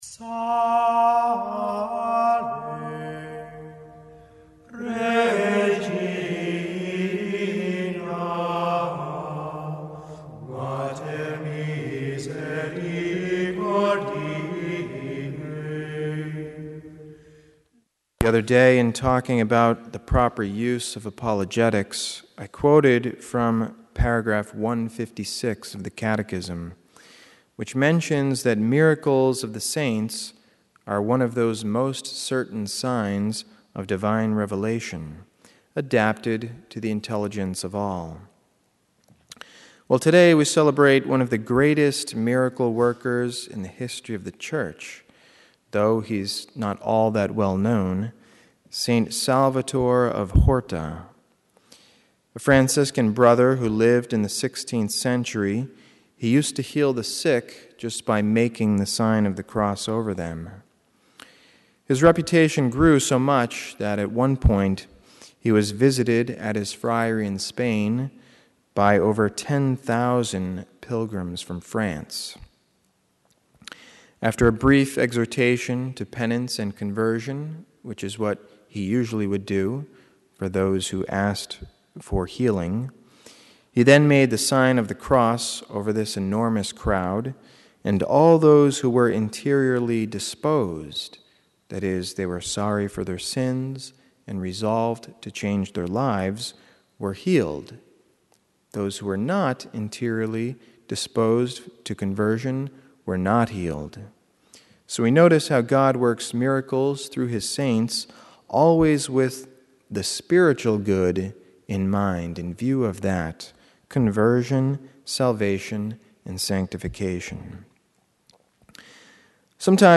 St. Salvatore of Horta - Mass: EF, Iustus Ut Palma - Readings: 1st: 1co 4:9-14 - Gsp: luk 12:32-34